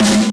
admin-fishpot/b_snare2_v127l4o5c.ogg at main